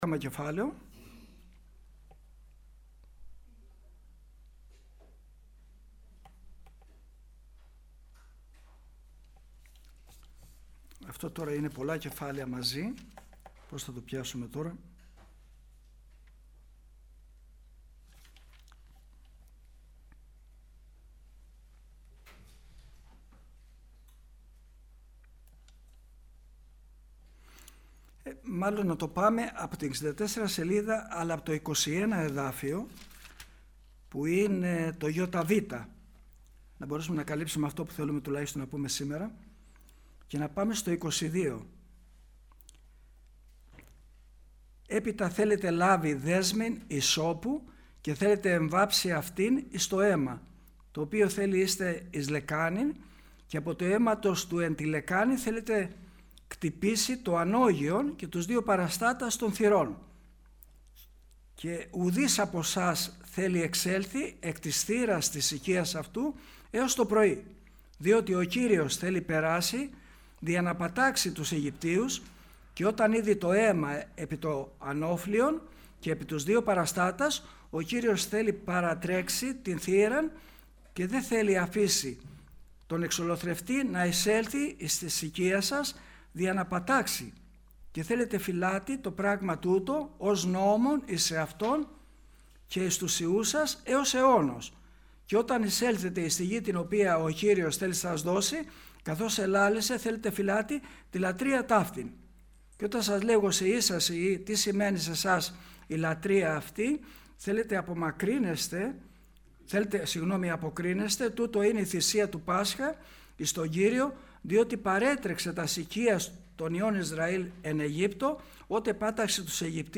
Κηρυγμα Ευαγγελιου